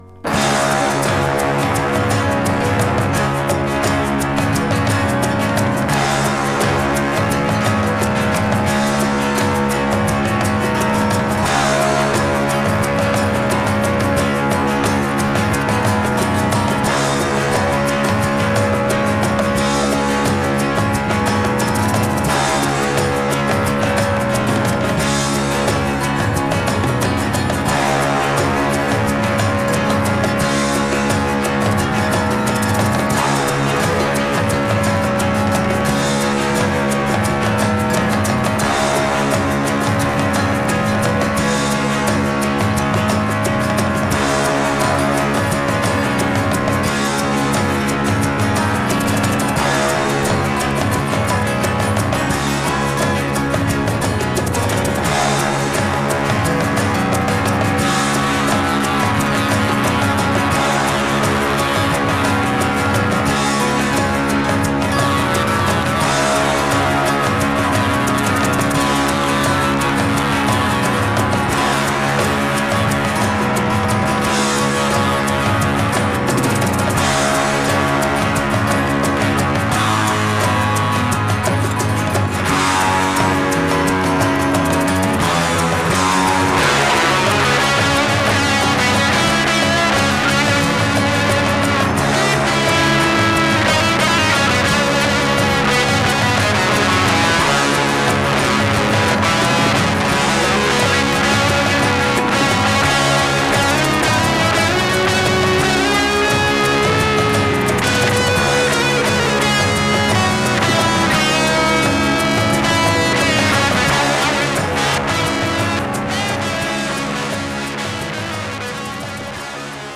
やけに漢臭さを感じさせるアングラなヘヴィー・サイケデリック・サウンド!